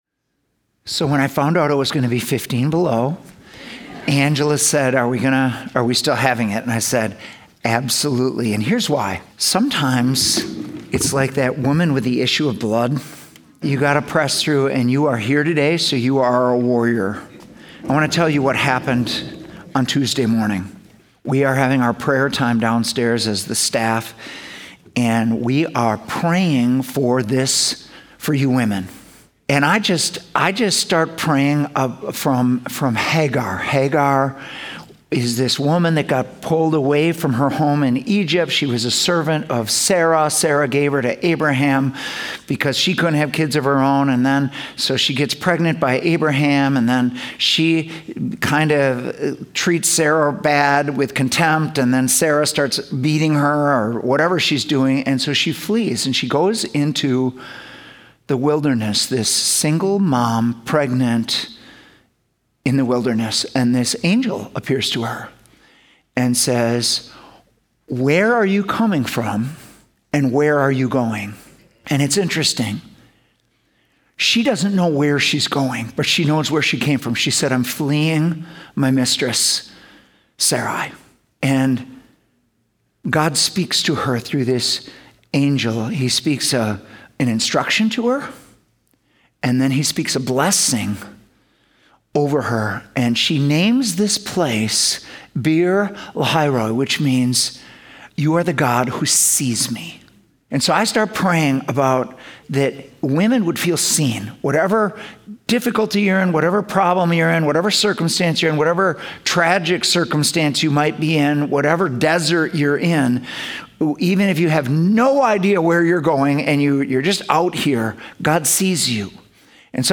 Stand Alone Messages @ City Church